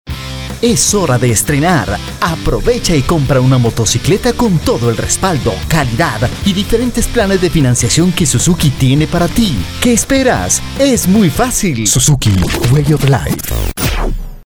Voz joven, dinamica, también puede ser amable e institucional; puedo producir totalmente su spot comercial o simplemente grabar la voz en off
Sprechprobe: Werbung (Muttersprache):
young voice, dynamic, can also be kind and institutional I can fully produce your commercial spot or simply record the voiceover